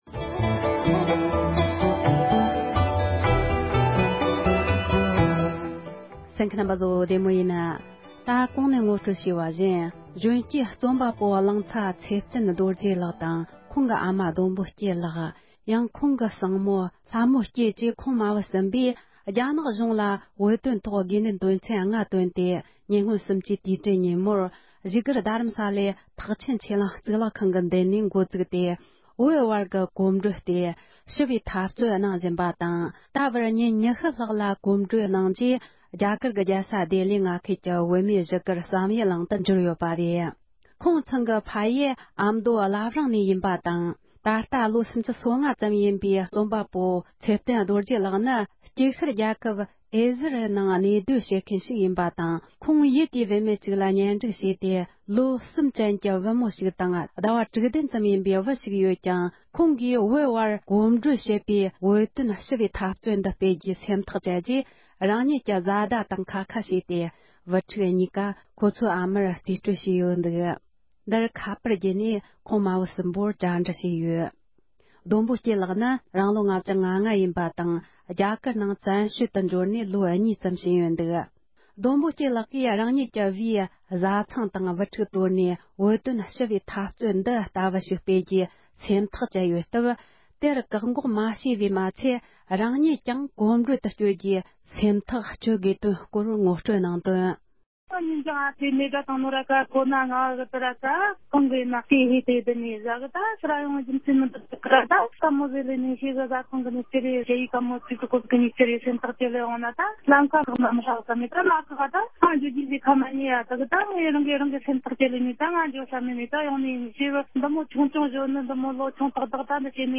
ཁོང་མ་བུ་ཚོར་བཅར་འདྲི་ཞུས་པར་གསན་རོགས་གནོངས༎